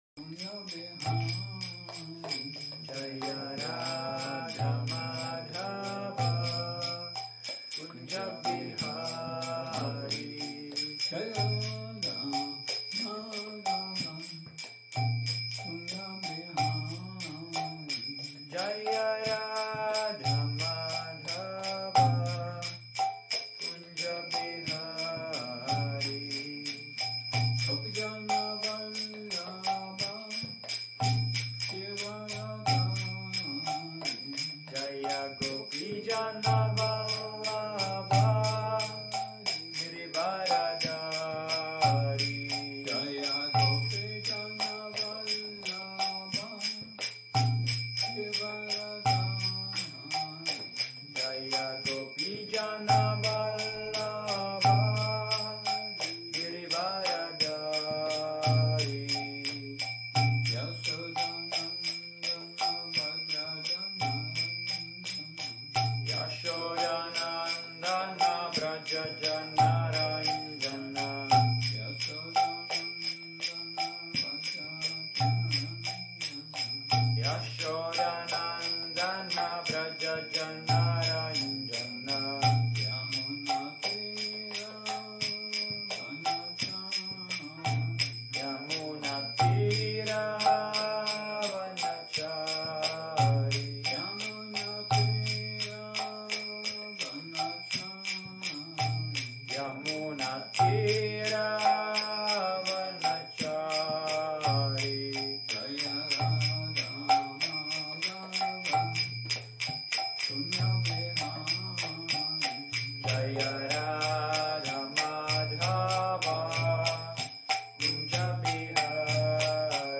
Seminář Attaining the bliss of chanting 01